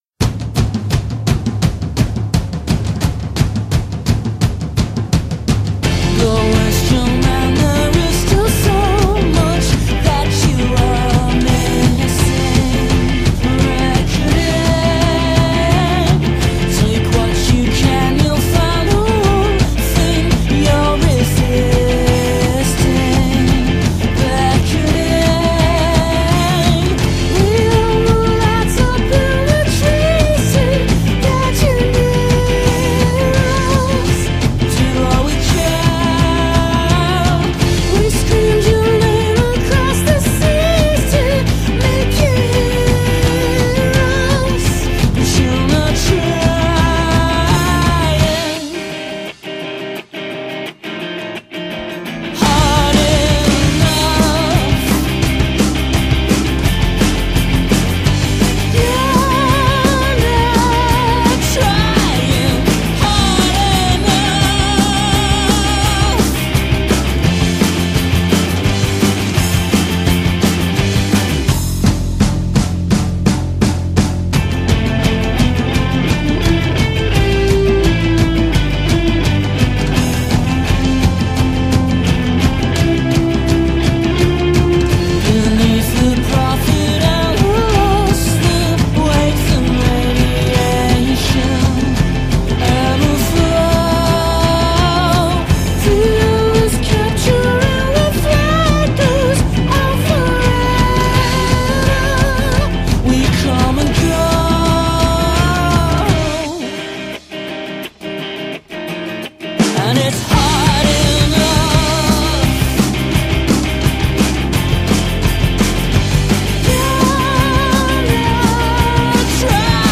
brilliant falsetto